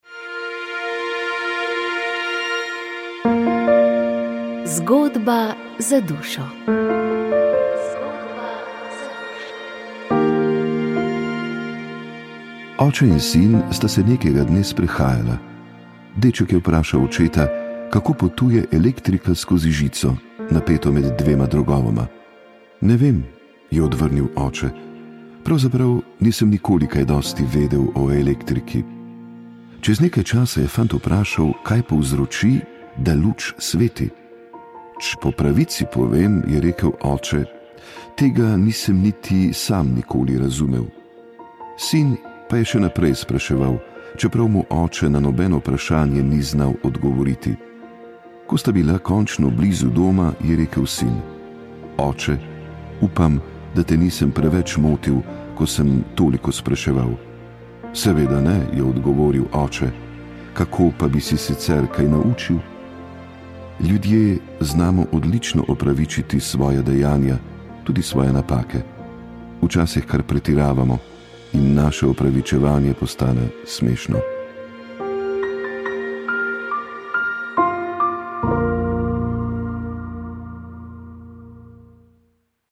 Iz oddaje pred leti smo izbrali posnetke pogovora z akademikom Gantarjem, dodali pa še odlomke iz njegove knjige Utrinki ugaslih sanj, ki je ieta 2005 izšla pri Slovenski matici.